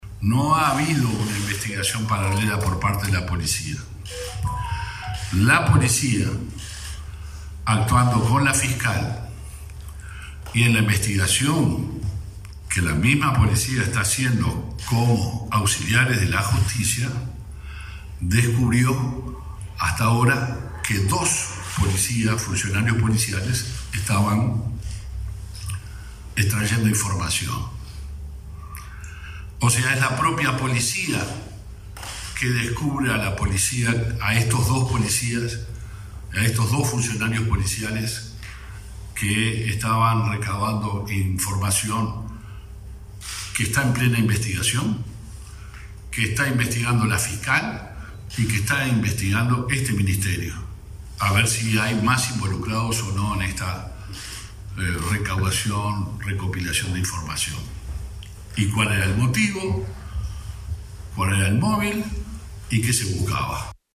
El ministro del Interior, Luis Alberto Heber, se refirió en conferencia de prensa al caso Penadés y dijo que “no es verdad” la “supuesta investigación paralela por parte de la Policía» para proporcionar información al ahora imputado, exsenador Gustavo Penadés.